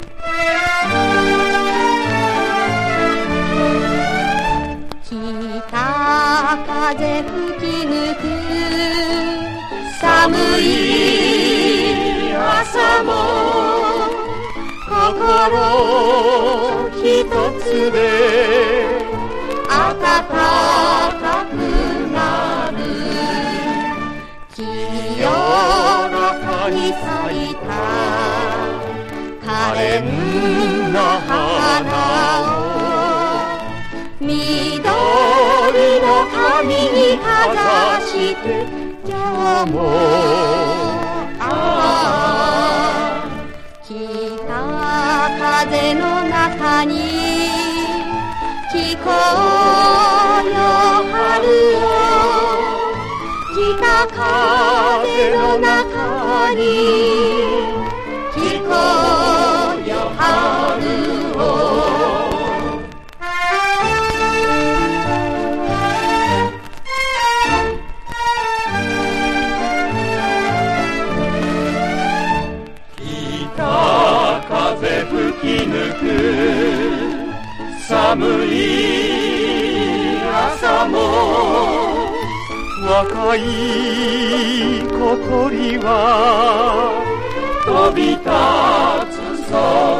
疑似ステレオで収録されてます。
和モノ / ポピュラー
所によりノイズありますが、リスニング用としては問題く、中古盤として標準的なコンディション。